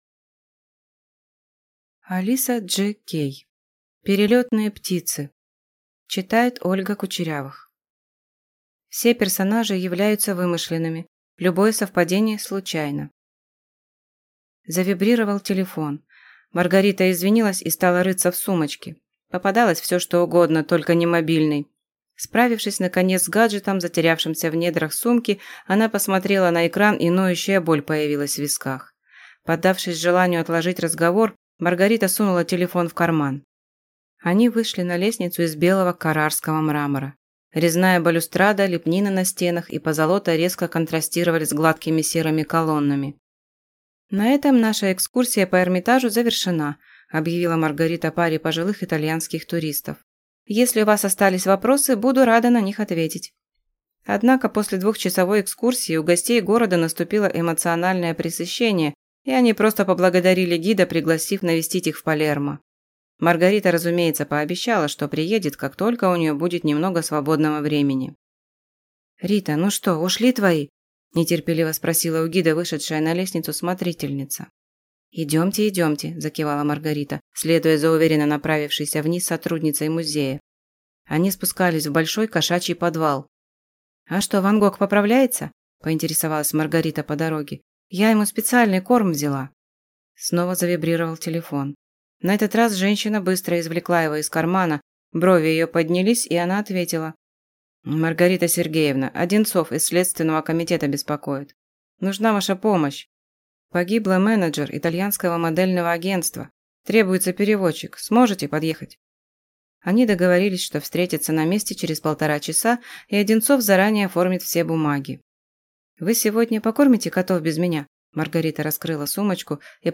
Аудиокнига Перелётные птицы | Библиотека аудиокниг